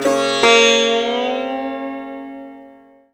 SITAR LINE45.wav